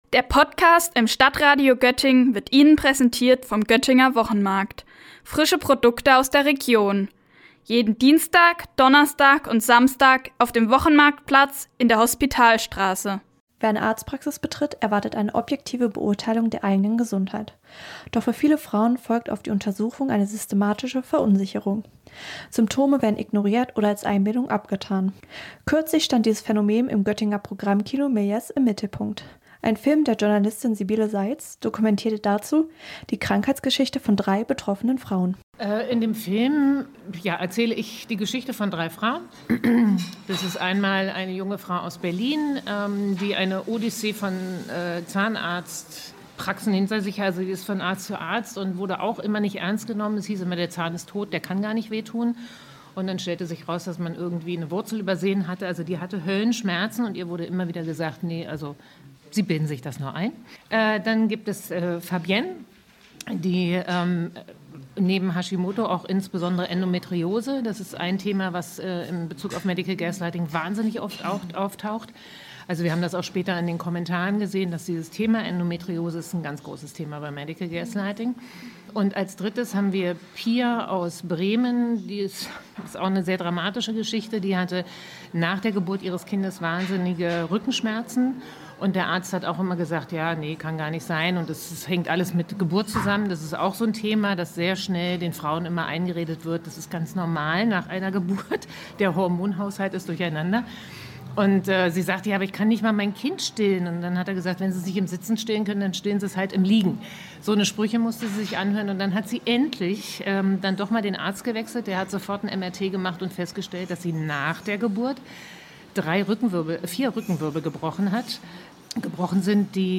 Schmerzen haben, aber vom Arzt nicht ernst genommen werden: Das ist der Kern von Medical Gaslighting. Dazu gab es im Göttinger Programmkino Méliès eine Veranstaltung, die dieses Problem beleuchtet hat.